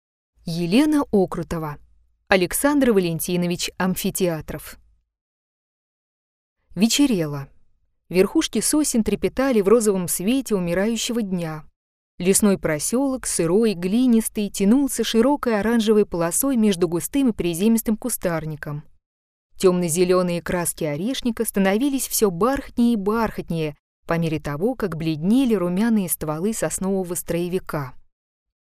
Аудиокнига Елена Окрутова | Библиотека аудиокниг